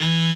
b_cello1_v100l4o4e.ogg